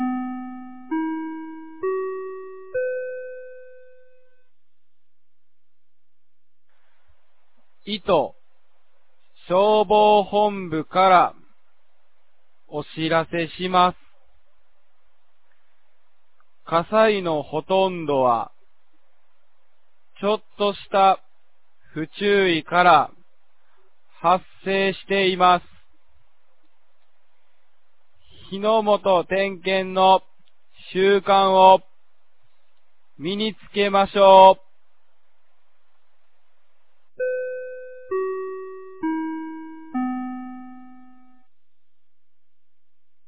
2025年05月19日 10時00分に、九度山町より全地区へ放送がありました。